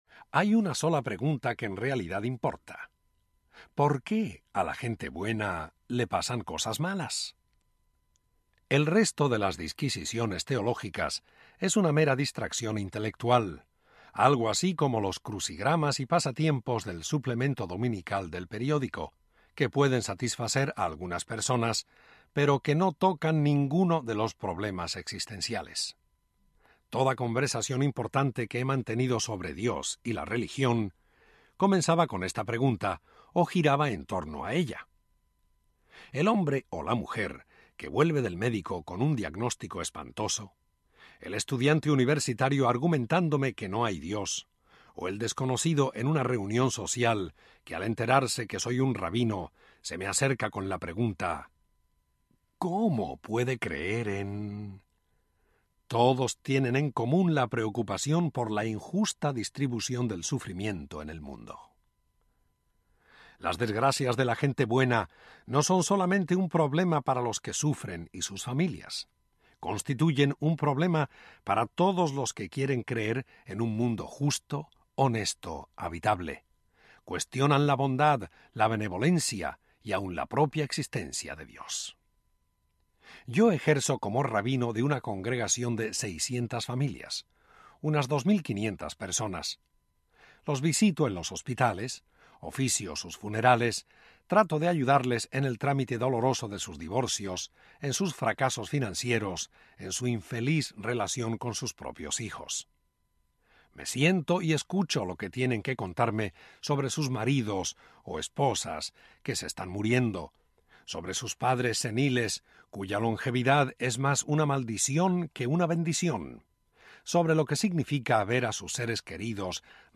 Narrator